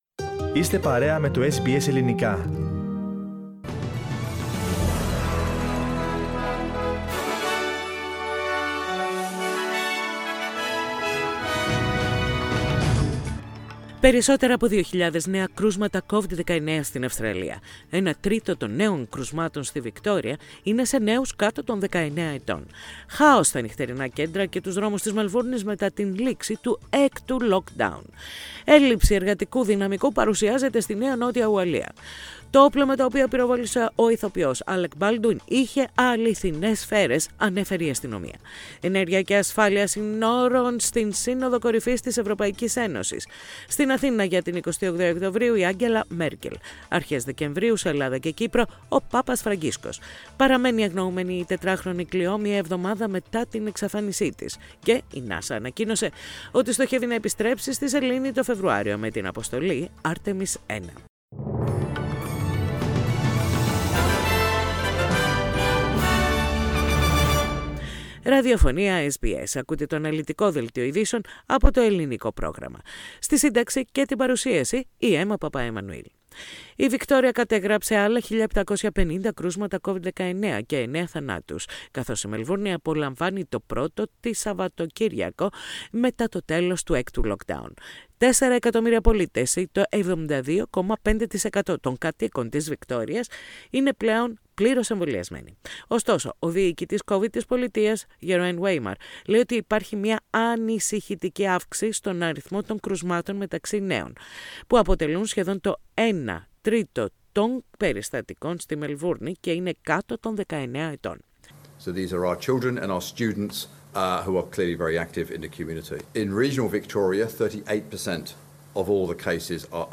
Ακούστε το αναλυτικό δελτίο ειδήσεων από το Ελληνικό πρόγραμμα της ραδιοφωνίας SBS, πατώντας play στο podcast που συνοδεύει την αρχική φωτογραφία.